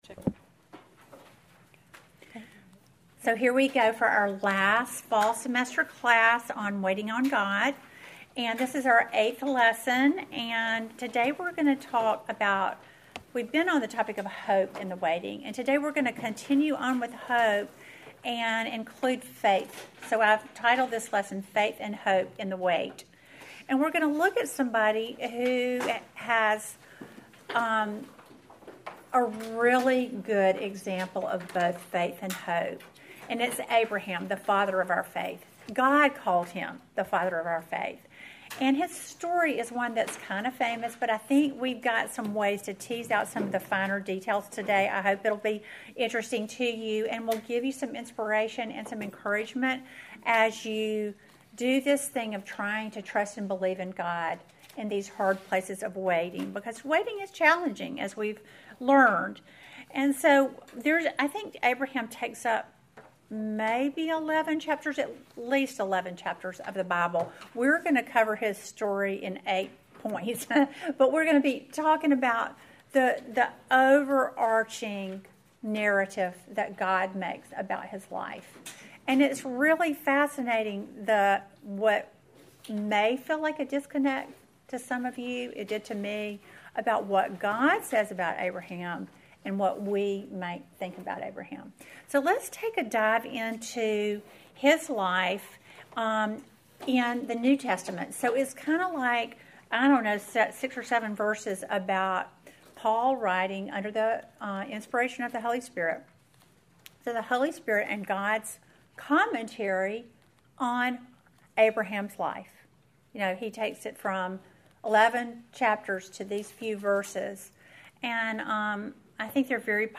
Welcome to the eighth lesson in our series WAITING ON GOD!